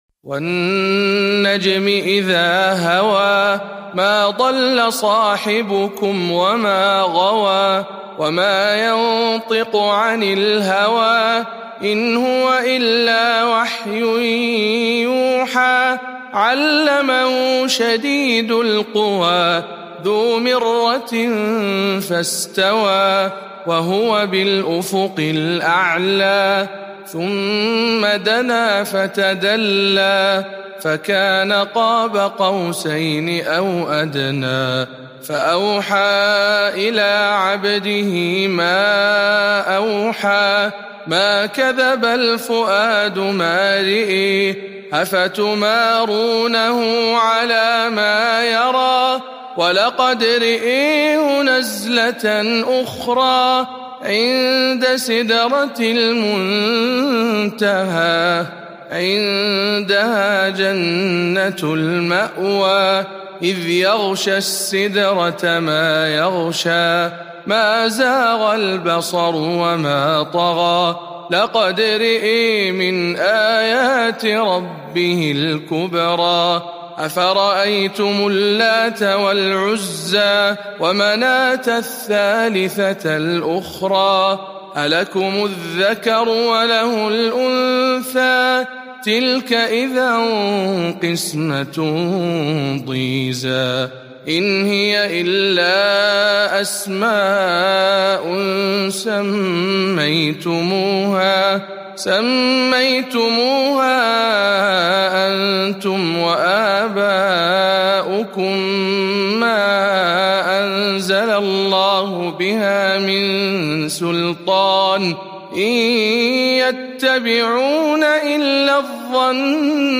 052. سورة النجم برواية شعبة عن عاصم